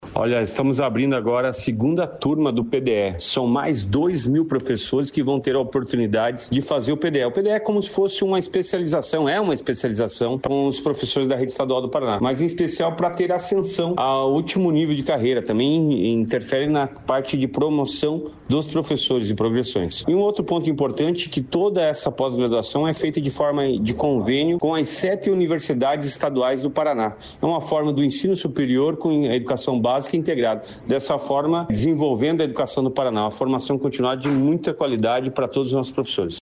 Sonora do secretário estadual de Educação, Roni Miranda, sobre formação continuada de professores no Litoral
RONI MIRANDA - SEMINÁRIO LITORAL.mp3